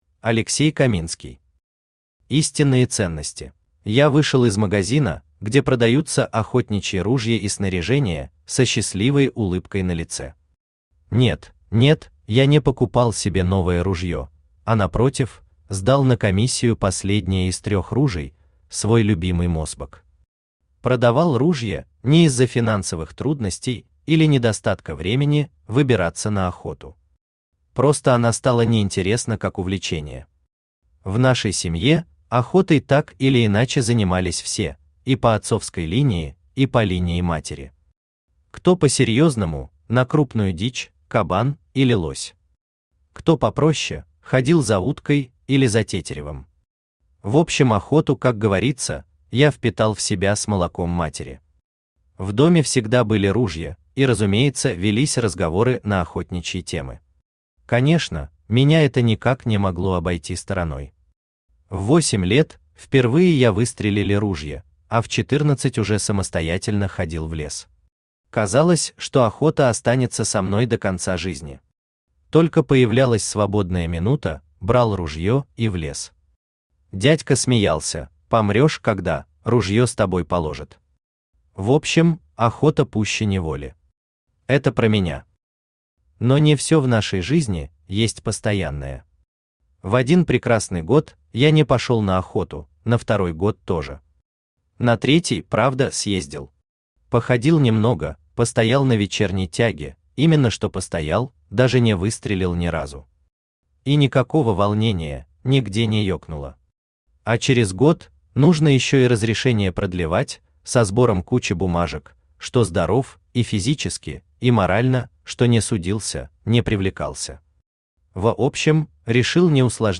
Аудиокнига Истинные ценности | Библиотека аудиокниг
Aудиокнига Истинные ценности Автор Алексей Каминский Читает аудиокнигу Авточтец ЛитРес.